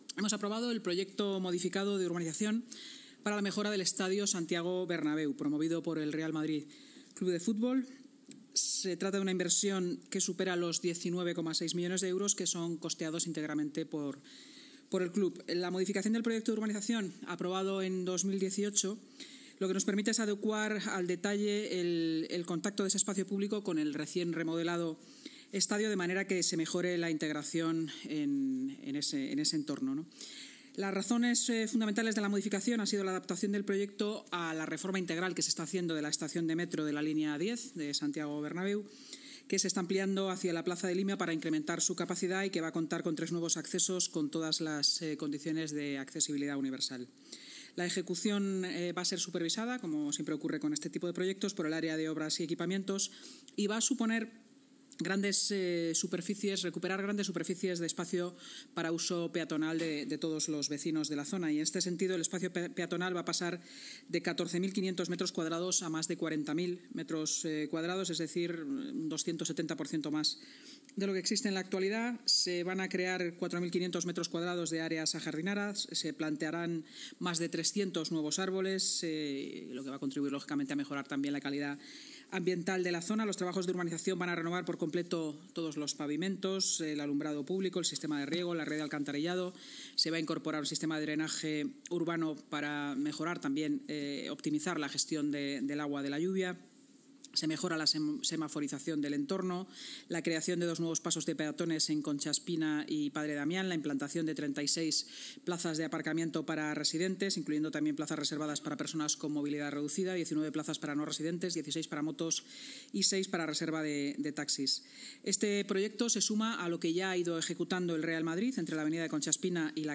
Así lo ha anunciado la vicealcaldesa de Madrid y alcaldesa en funciones, Inma Sanz, tras la reunión semanal de la Junta de Gobierno. La inversión de las actuaciones supera los 19,6 millones de euros, que serán costeados íntegramente por el club.